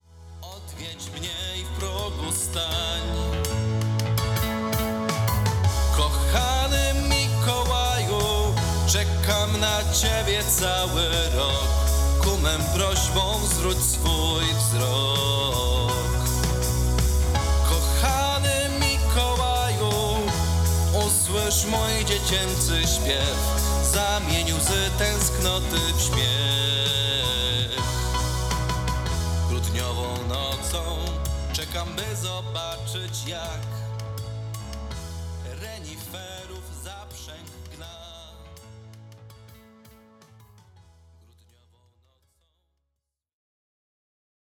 Urocza piosenka o Mikołaju.